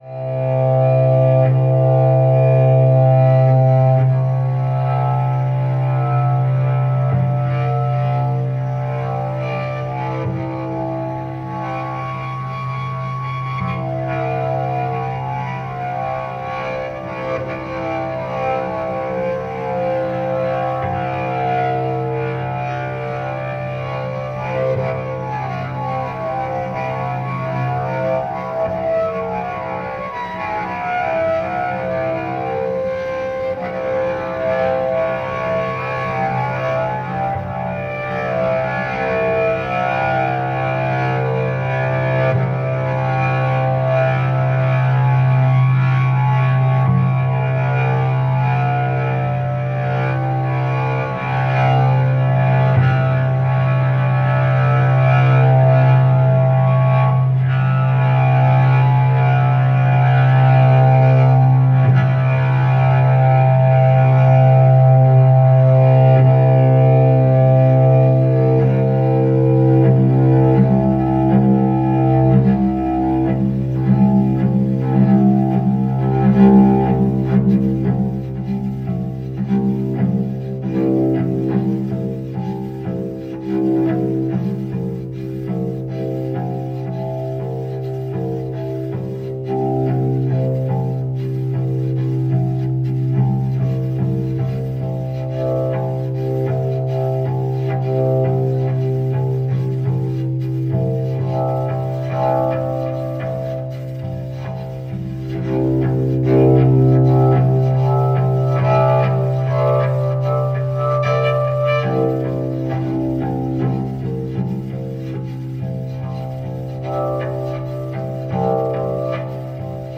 チェロの音色の可能性を独自の研究でコントロール、完全に物音化した抽象反復やら鬼気迫る摩擦音を様々な角度より放射。